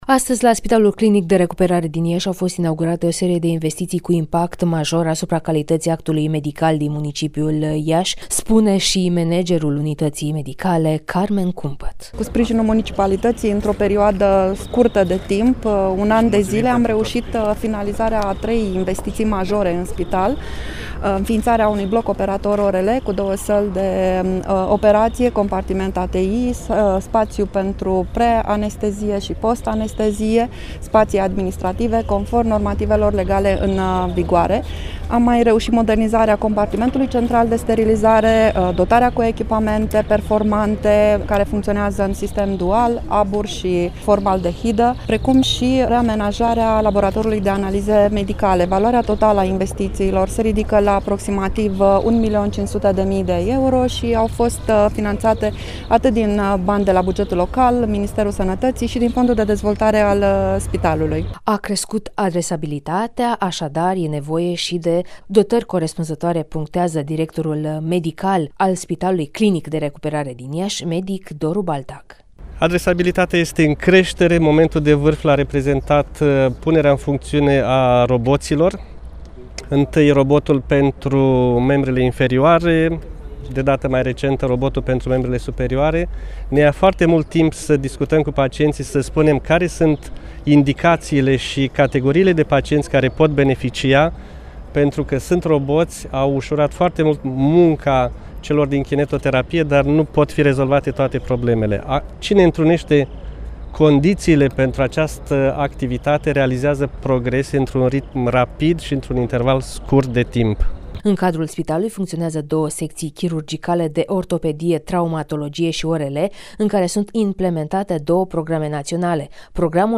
(REPORTAJ) Investiţii la Spitalul Clinic de Recuperare Iaşi